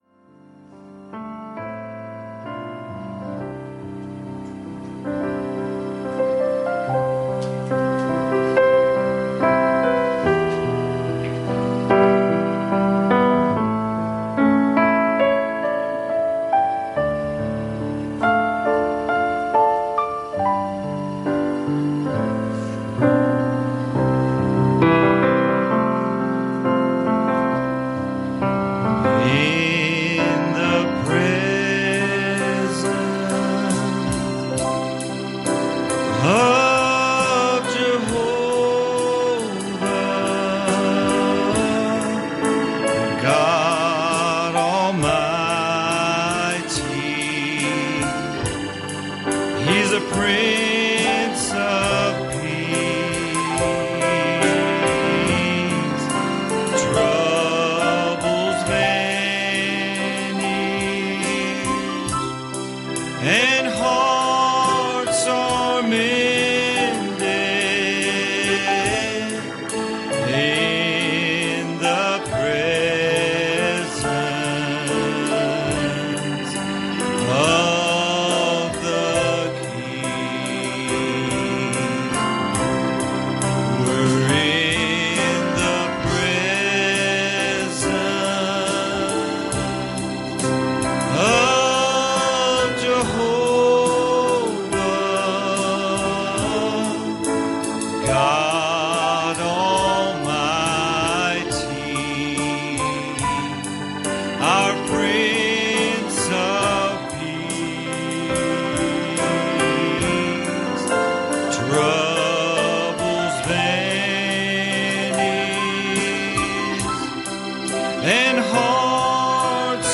Passage: Revelation 1:12 Service Type: Sunday Evening